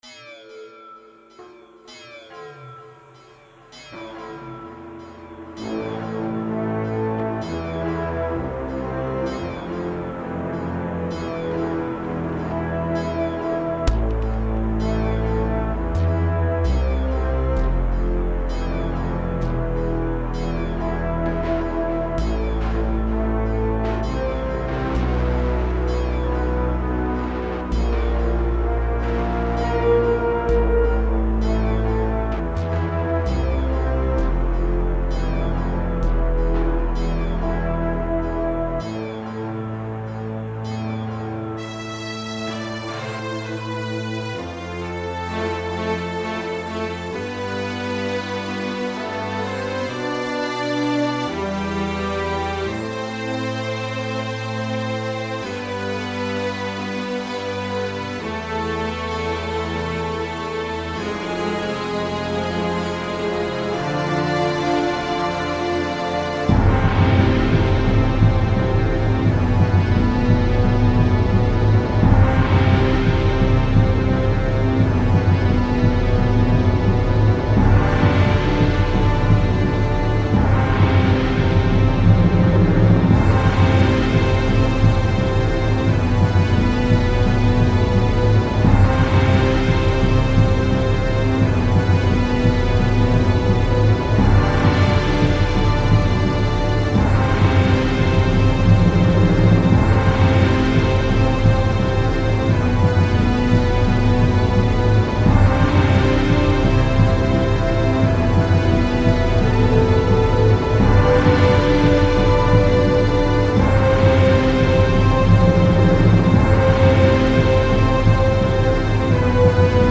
Постапокалиптическое произведение